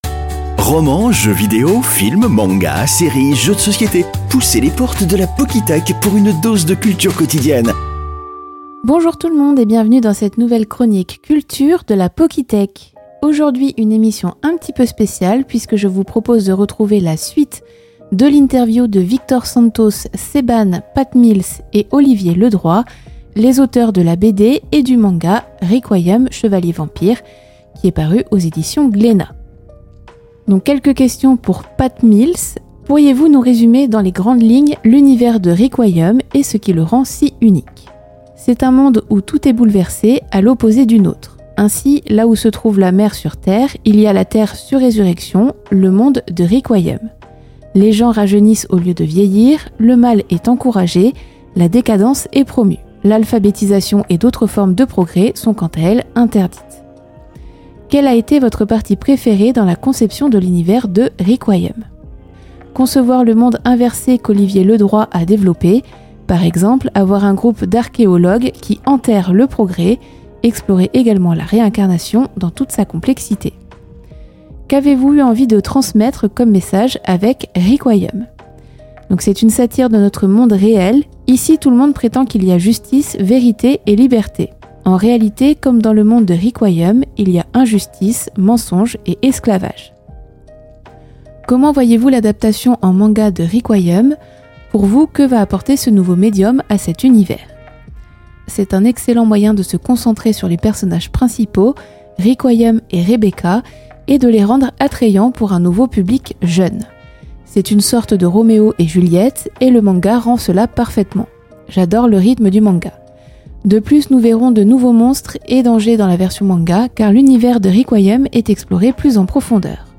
La Pockythèque - Interview des auteurs de la BD et du manga "Requiem, chevalier vampire" (Partie 2)